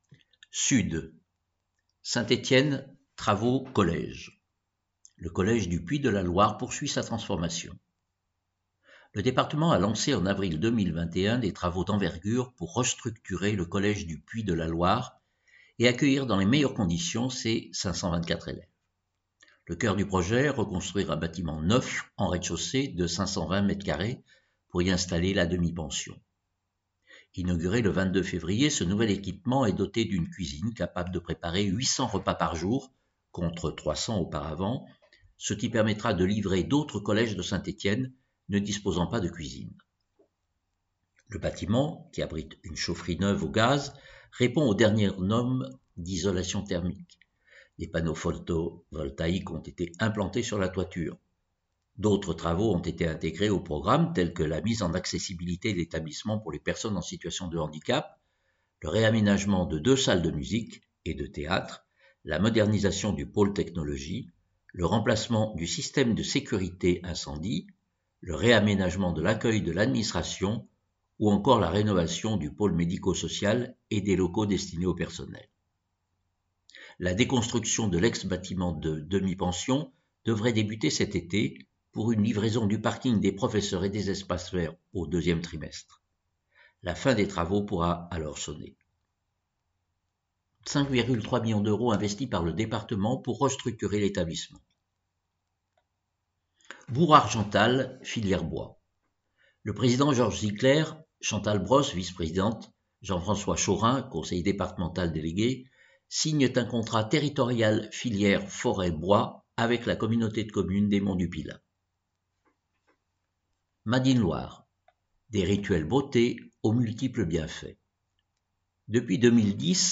Loire Magazine n°155 version sonore